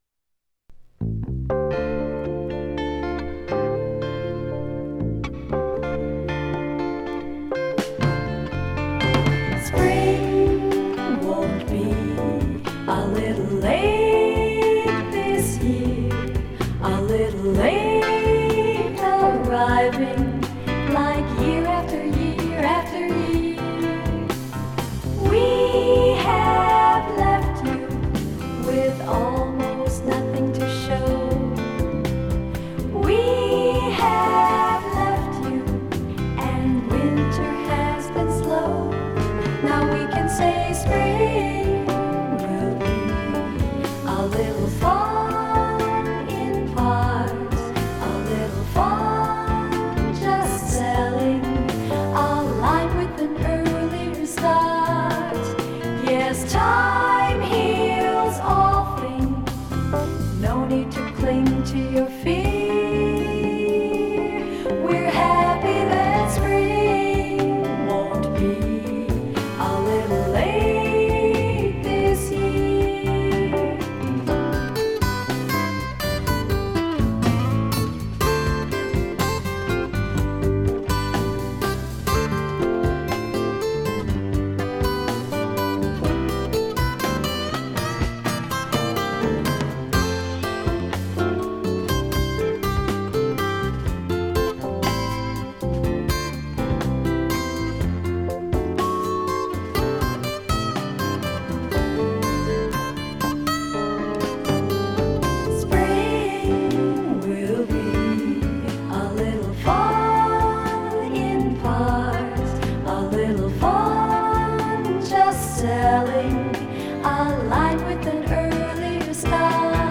Spring won't be a little late this year, 1973. 1 master sound disc (5 minutes, 28 seconds): 45 rpm; 7 inches and 1 user audio file: MP3 (4.9 MB).
Menswear promotional record.